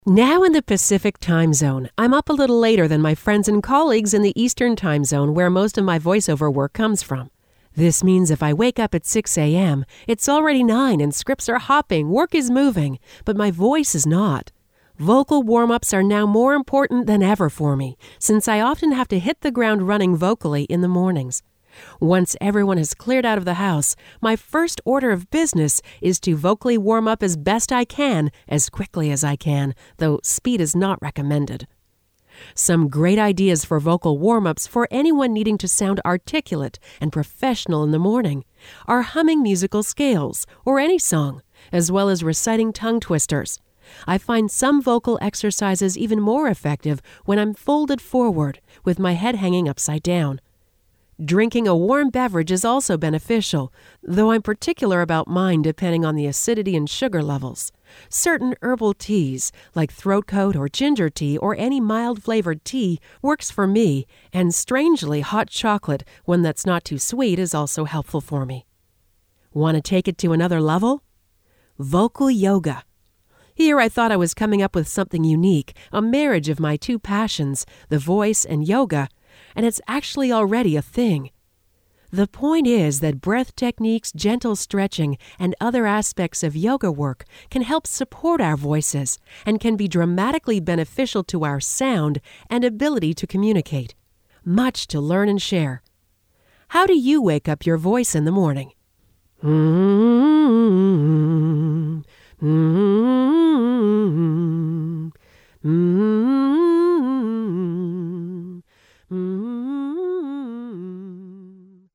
Good Morning Starshine* (audio version of blog below) Now in the Pacific Time Zone, I’m up a little later than my friends and colleagues in the Eastern Time Zone where most of my voice work comes f…